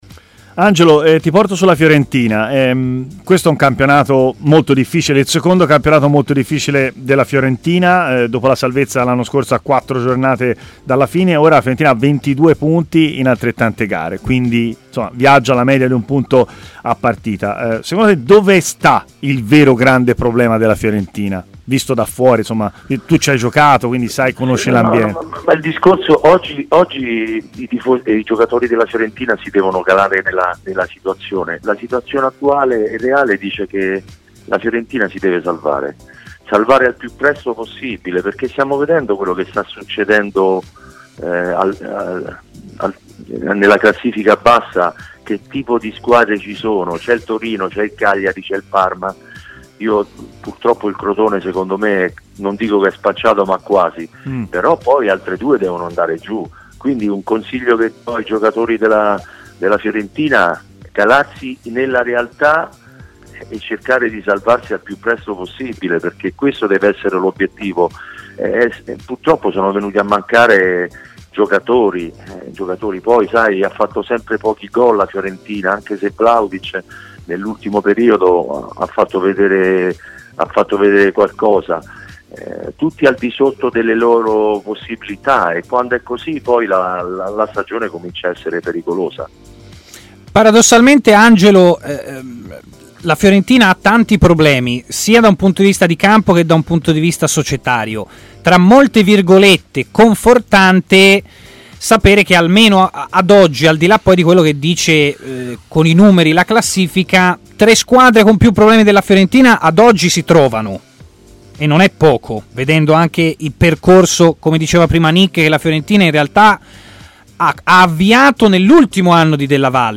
L'ex centrocampista Angelo Di Livio ha parlato ai microfoni di TMW Radio, intervenendo in diretta nel corso della trasmissione Stadio Aperto.